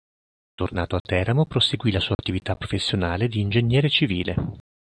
pro‧fes‧sio‧nà‧le
/pro.fes.sjoˈna.le/